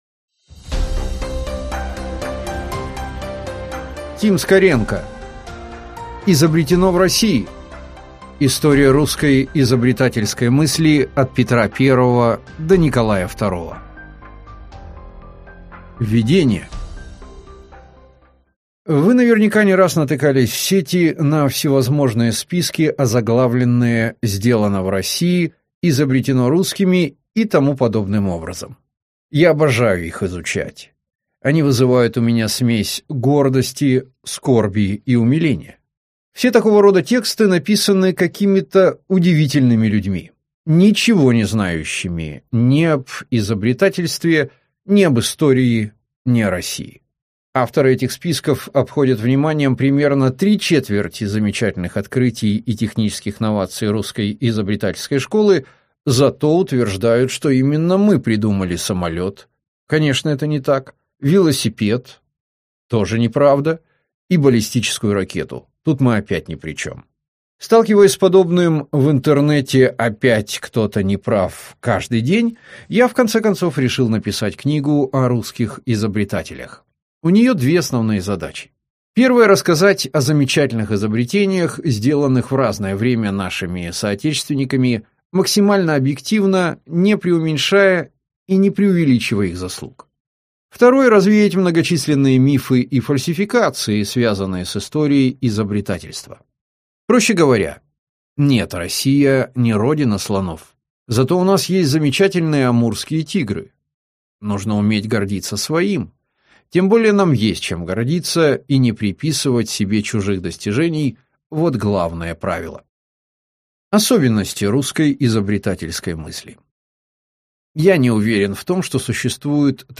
Аудиокнига Изобретено в России: История русской изобретательской мысли от Петра I до Николая II | Библиотека аудиокниг